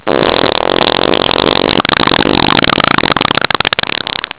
fart7.wav